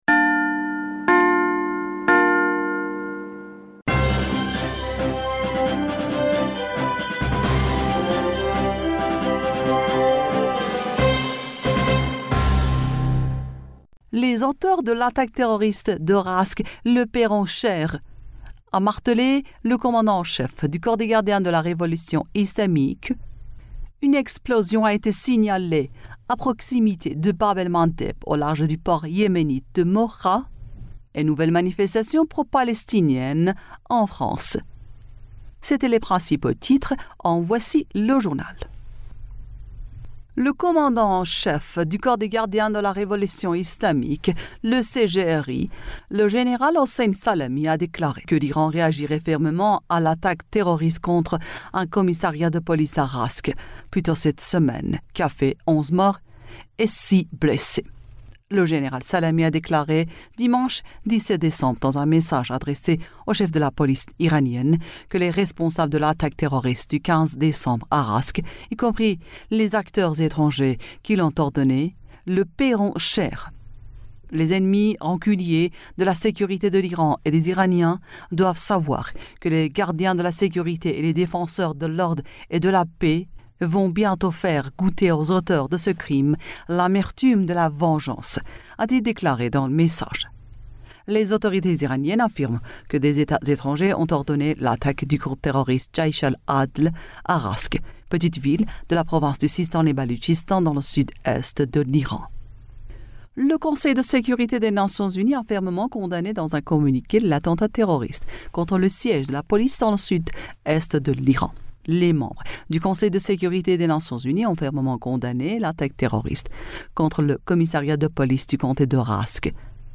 Bulletin d'information du 18 Decembre 2023